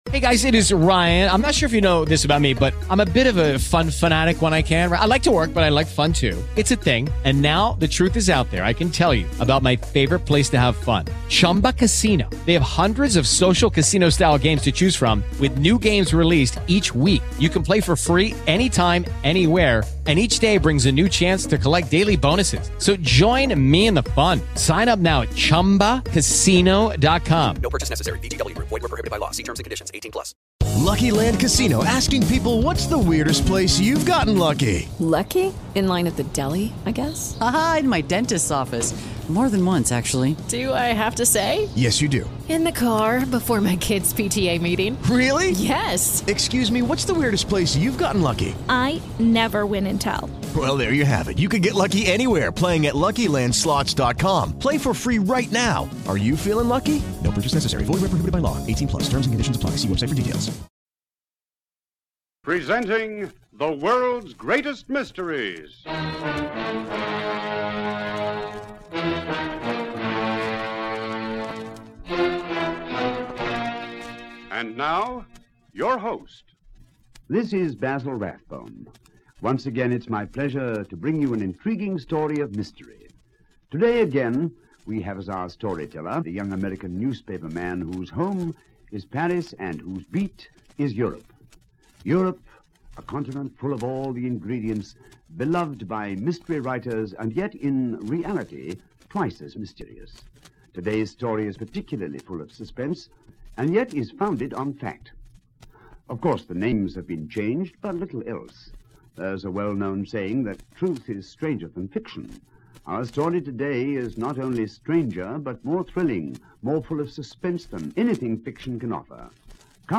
Mystery, espionage